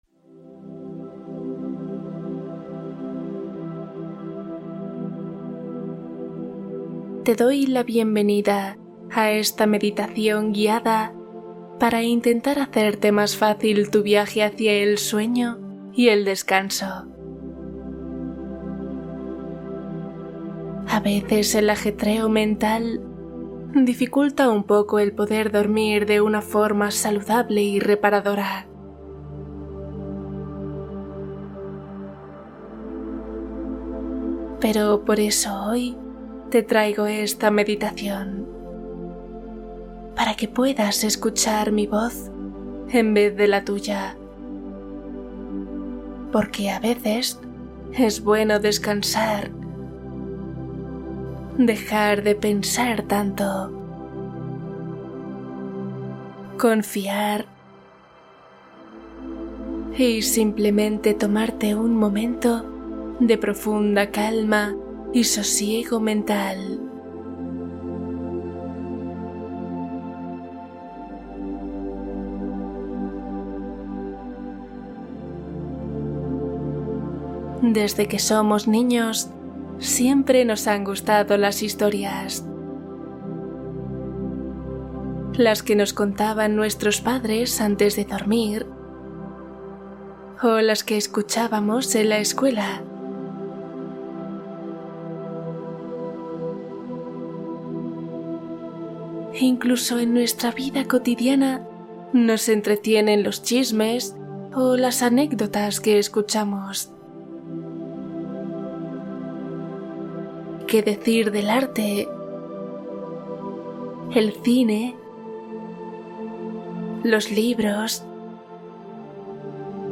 Cuento + meditación 1 hora | Para insomnio y descanso profundo